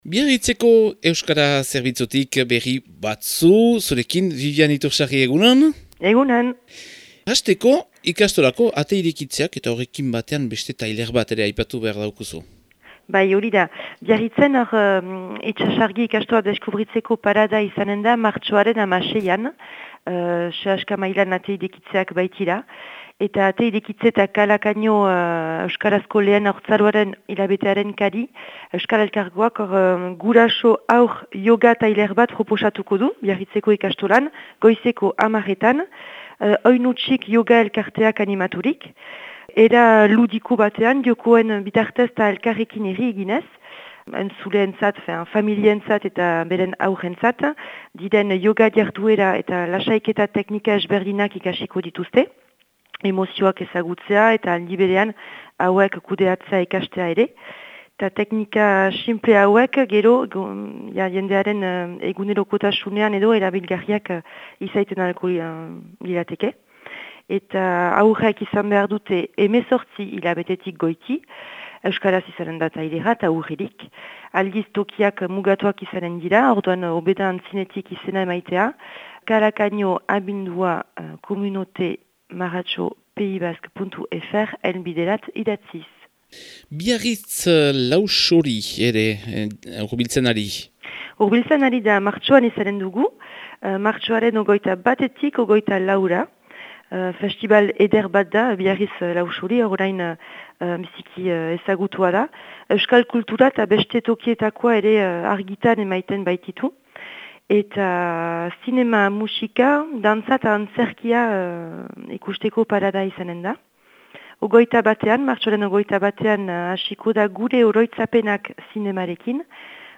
Otsailaren 28ko Biarritzeko berriak